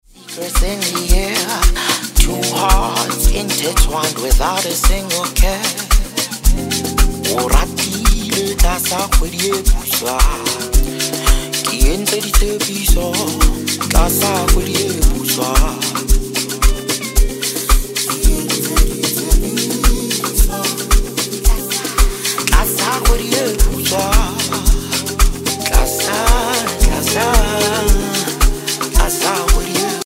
a mesmerizing and finely produced tune